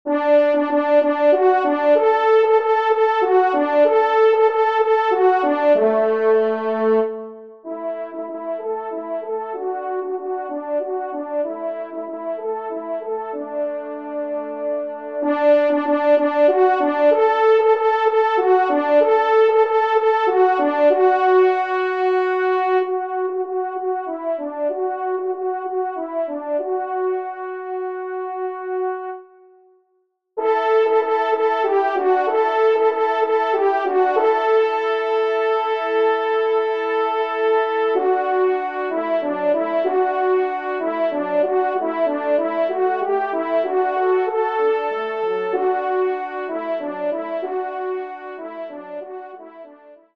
Genre :  Divertissement pour Trompes ou Cors en Ré
1e Trompe